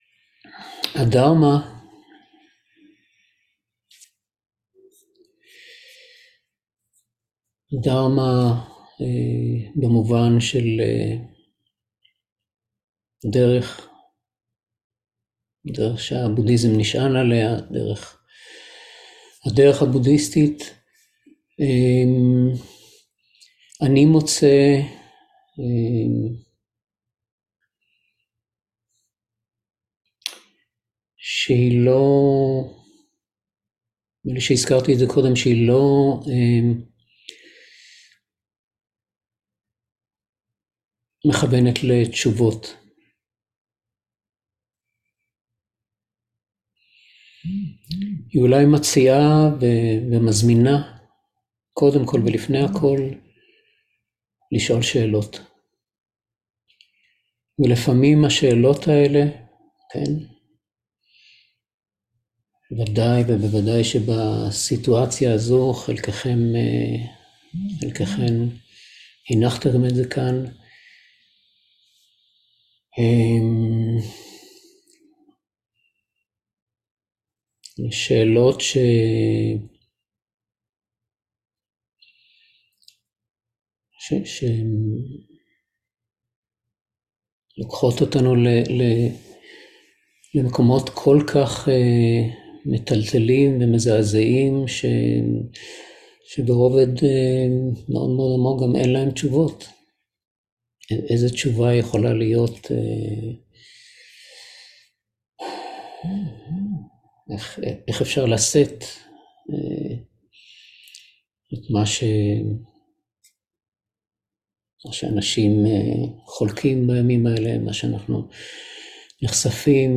30.10.2023 - מרחב בטוח - אפשרות הבחירה שיש לנו בכל רגע (חלק ב) - לימוד